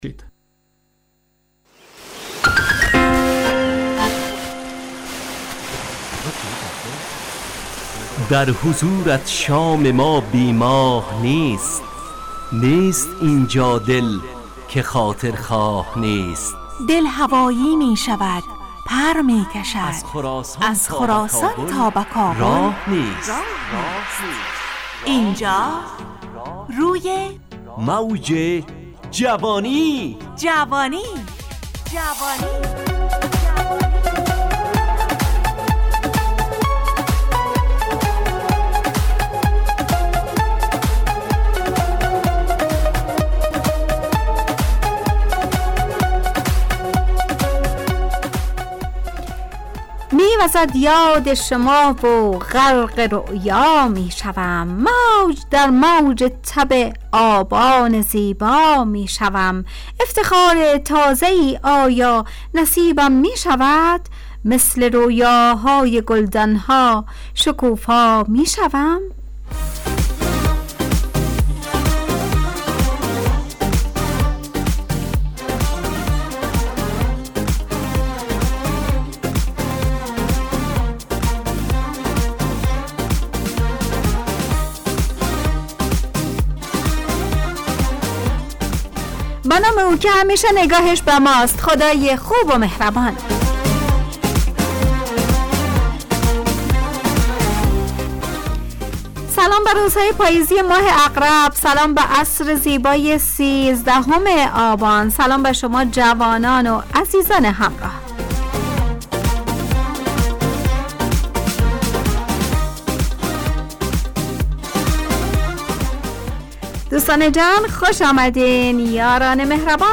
برنامه شادو عصرانه رادیودری
همراه با ترانه و موسیقی مدت برنامه 70 دقیقه .